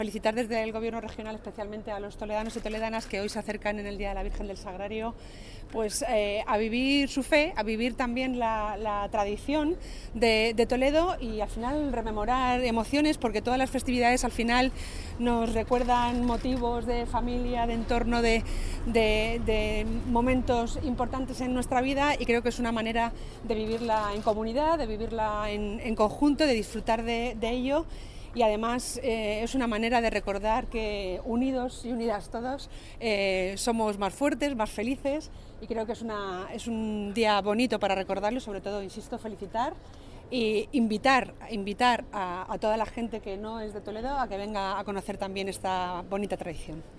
• Durante su intervención, la portavoz regional ha invitado también a toda la gente que no es de Toledo a que venga a conocer dicha tradición.
estherpadilla_felicitacion_virgen_sagrario.mp3